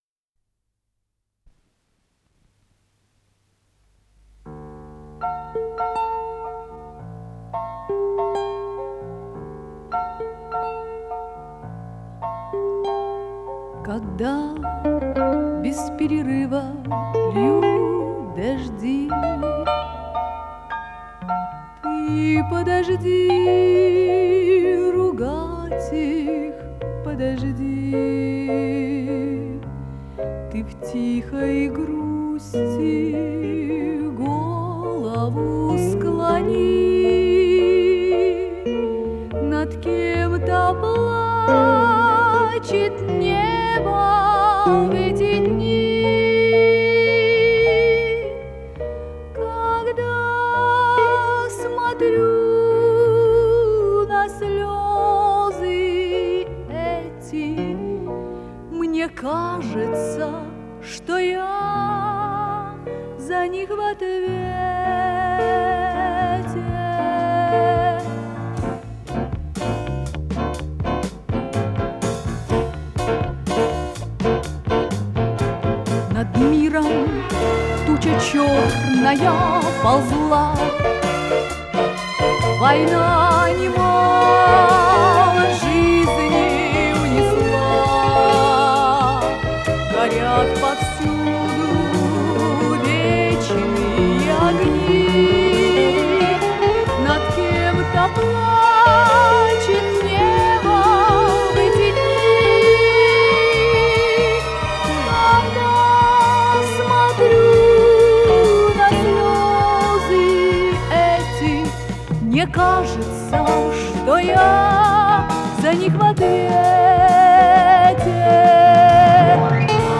с профессиональной магнитной ленты
РедакцияМузыкальная
ВариантДубль моно